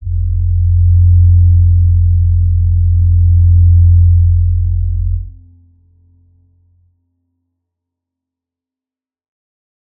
G_Crystal-F2-mf.wav